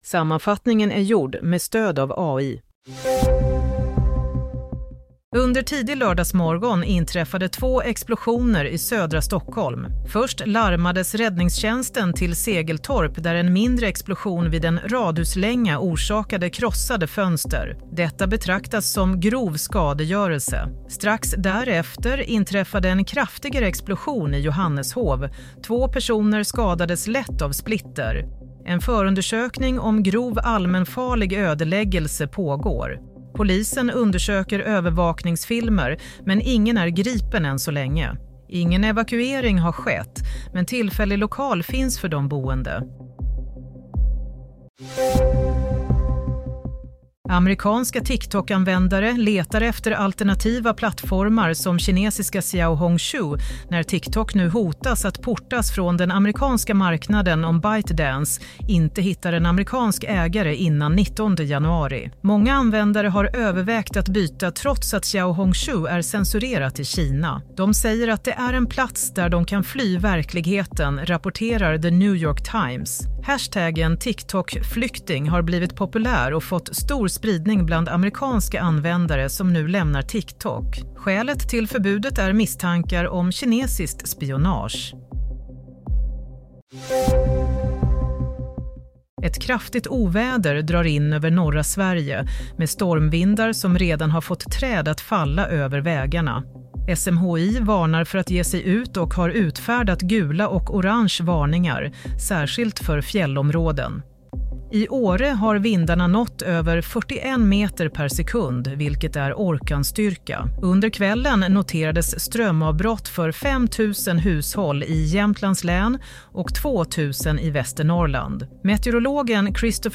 Nyhetssammanfattning - 18 januari 07:00